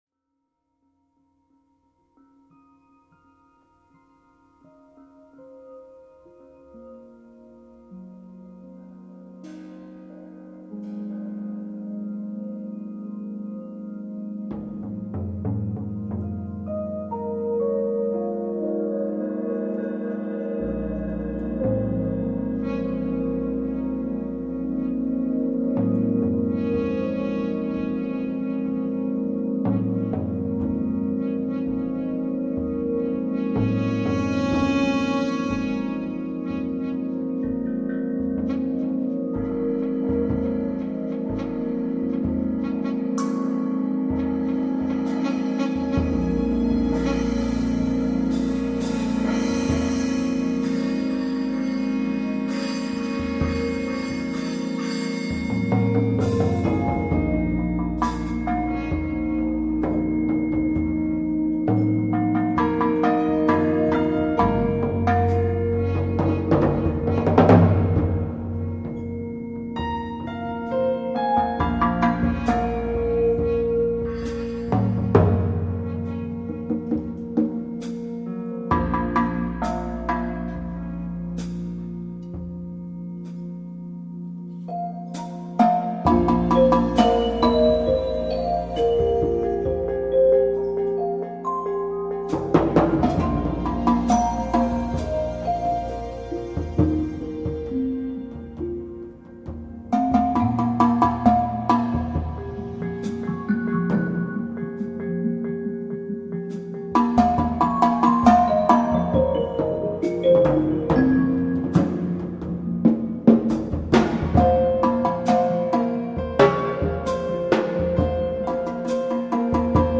Live Improvisations at the Percussion Atelier on May 18th 2001 Real Time Composition and Arrangement
Drums, Percussion, Bali Gongs, Balaphon
Grand Piano, Synthesizer, Sylophon, Bass Pedals